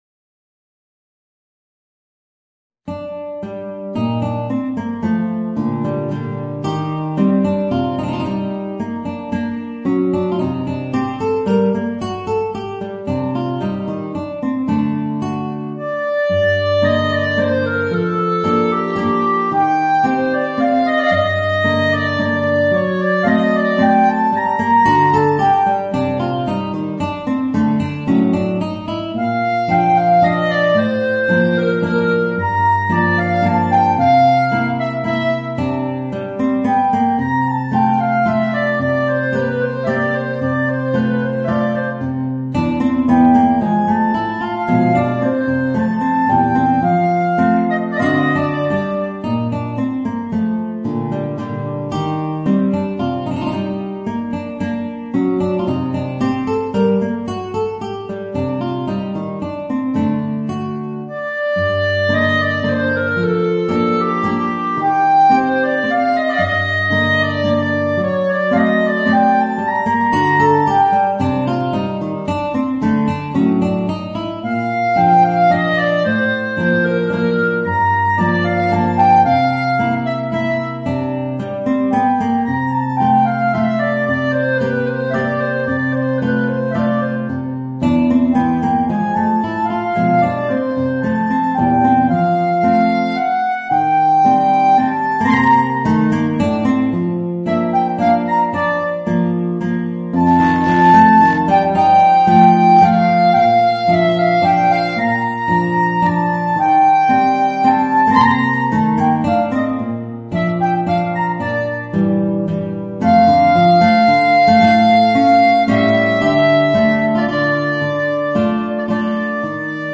Voicing: Clarinet and Guitar